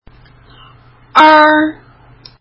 そり舌(儿化)母音
er 日本語の｢エ｣の口で｢オー｣と言いながら舌先を上にそり上げるイメージ。